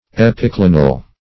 Search Result for " epiclinal" : The Collaborative International Dictionary of English v.0.48: Epiclinal \Ep`i*cli"nal\, a. [Pref. epi- + Gr.